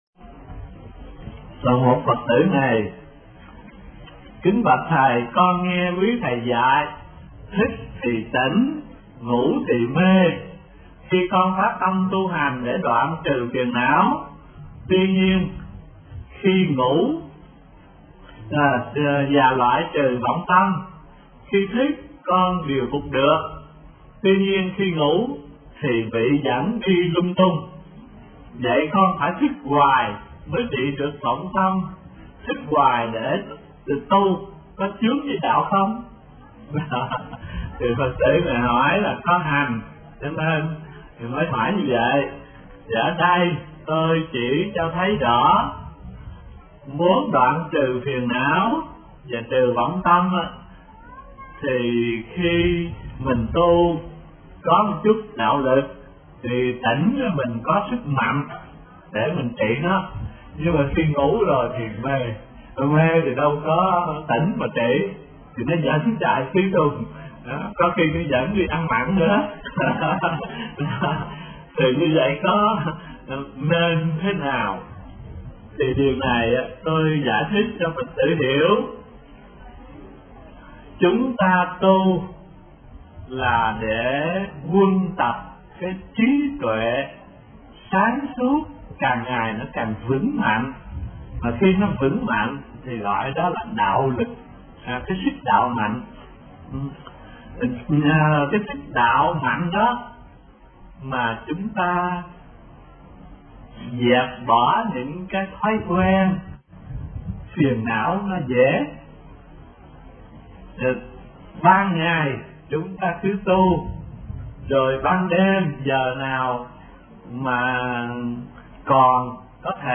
Có Phải Thức Hoài Để Trị Vọng Tâm Không – Tham vấn HT Thanh Từ 62